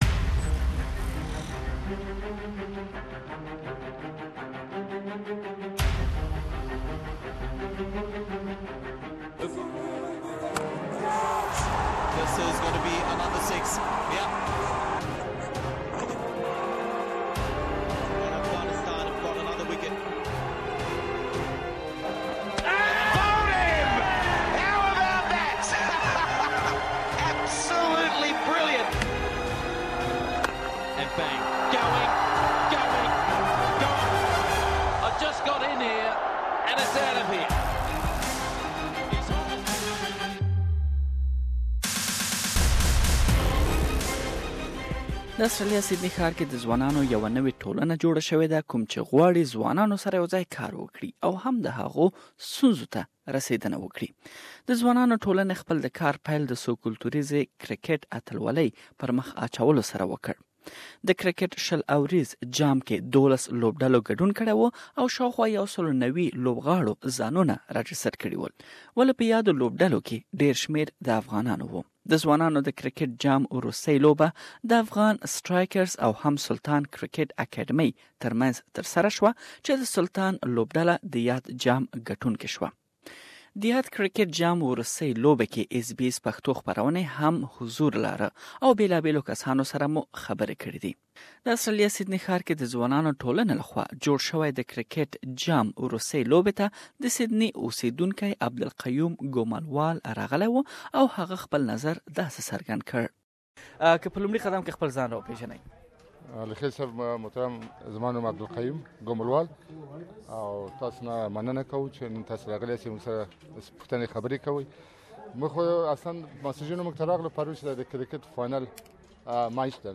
SBS Pashto program was present at the Finals and have made a full report that you can listen to it here.